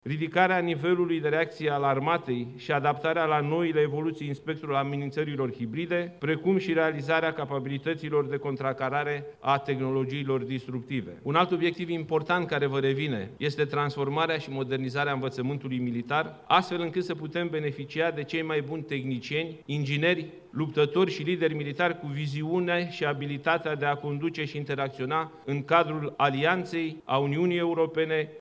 La rândul său, fostul şef al Armatei, generalul Nicolae Ciucă, acum ministru, i-a recomandat generalului Daniel Petrescu să crească viteza de reacţie a militarilor.